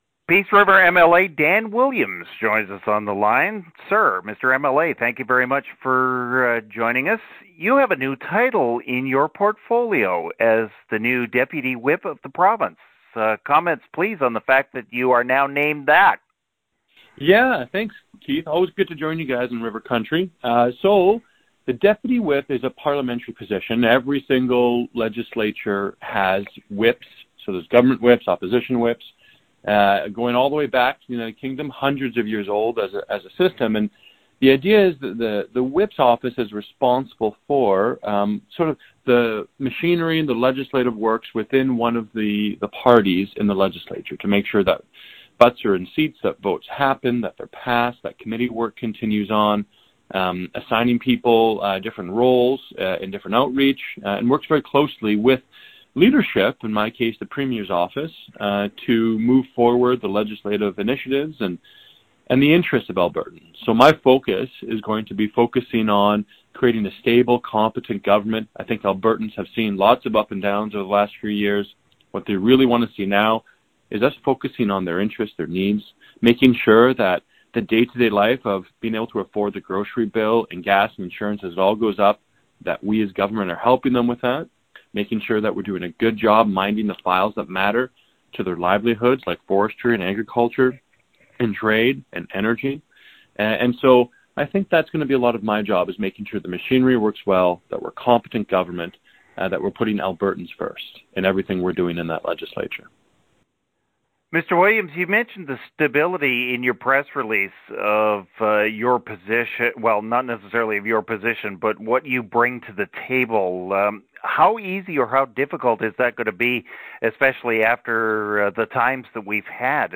MLA Williams new Deputy WHIP (Dan Williams Interview)